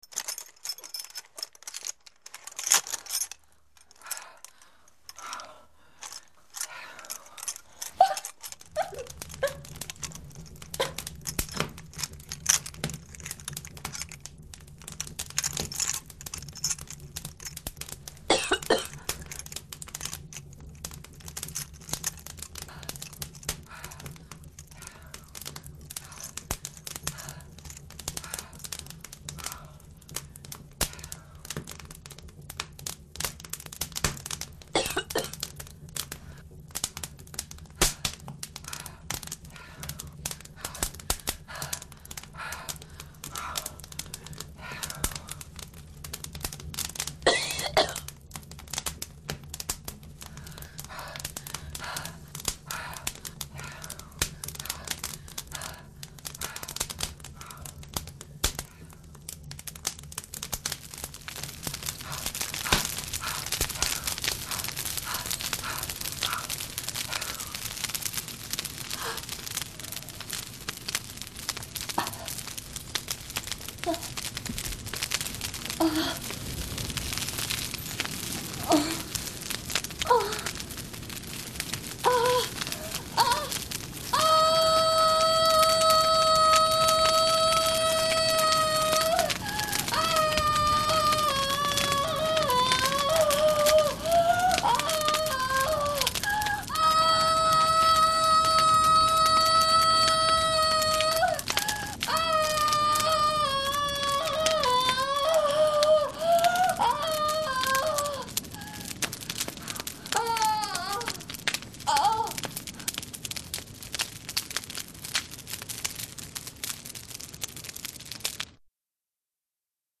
Just close your eyes and let your imagination take over!